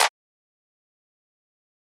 SizzClap2.wav